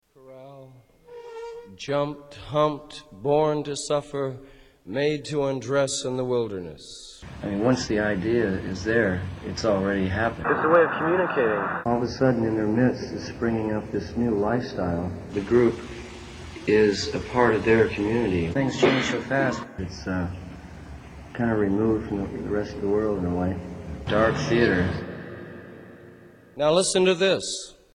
Tags: Jim Morrison quotes The Doors Jim Morrison Poems Jim Morrison spoken word Poetry CD